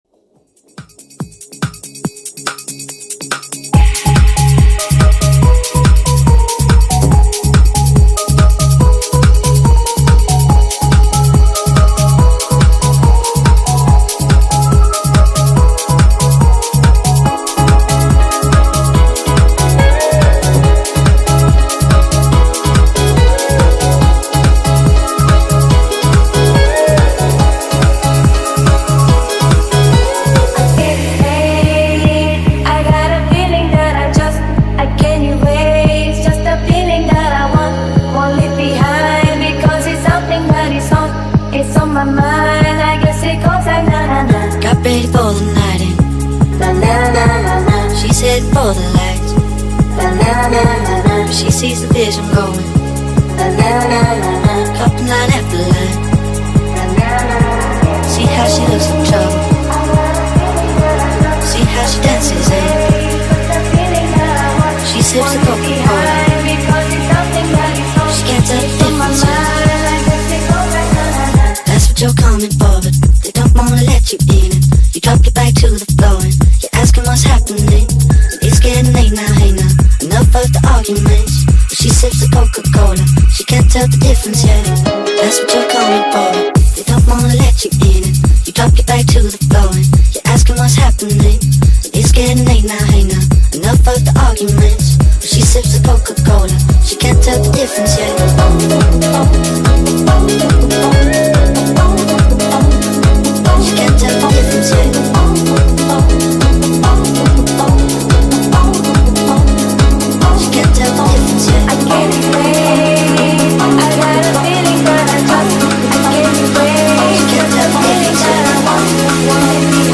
• Качество: 320 kbps, Stereo
TikTok remix